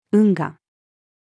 運河-female.mp3